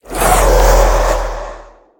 minecraft / sounds / mob / wither / idle4.ogg